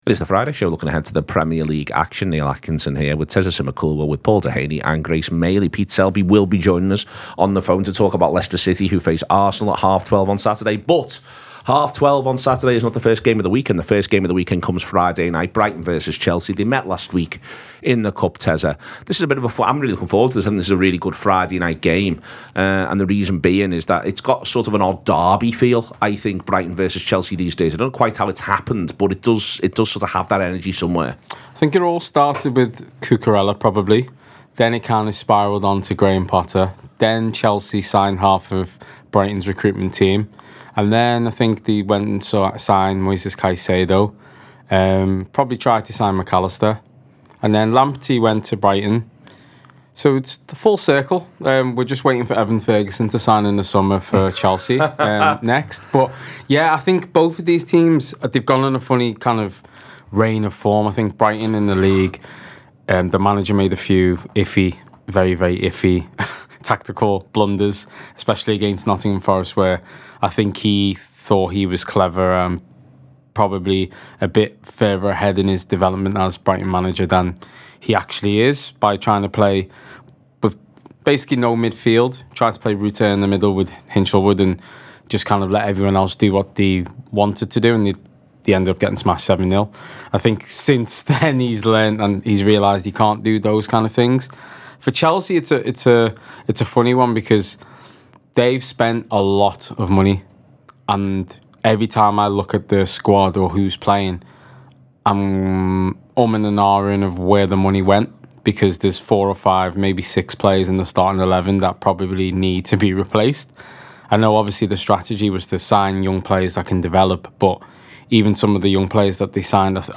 The Anfield Wrap’s preview show ahead of another massive weekend of football.